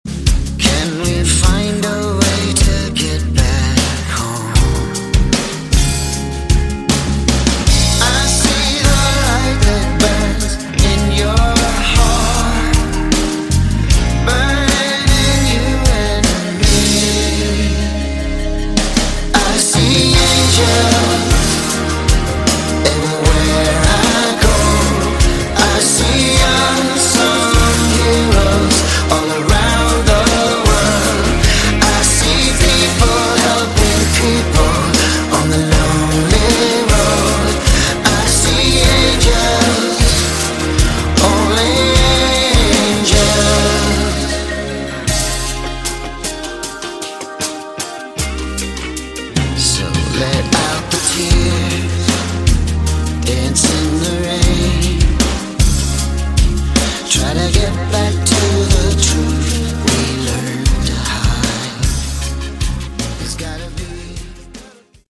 Category: Melodic Rock
lead vocals, guitars, piano
drums, percussion, electronics
keyboards
electric and acoustic bass, vocals